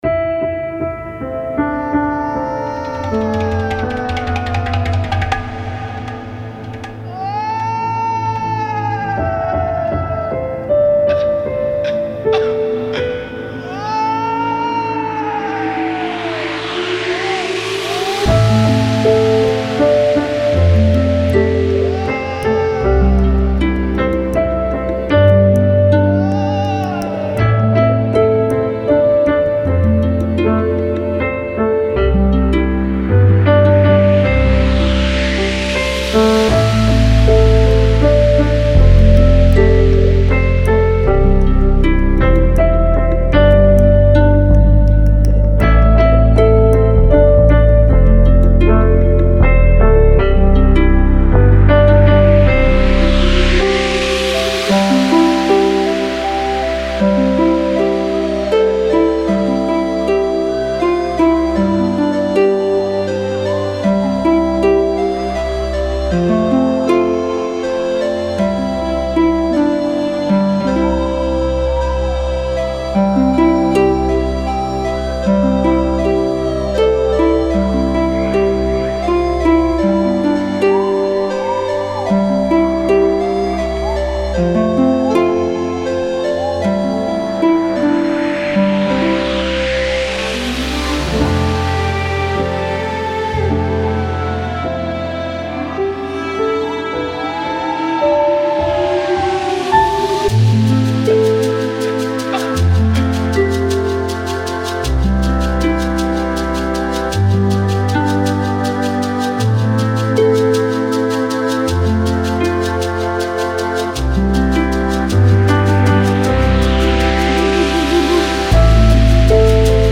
nzira-instrumental.mp3